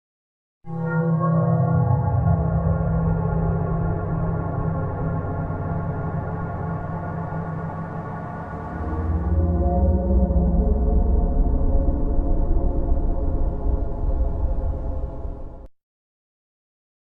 FNAF Ambience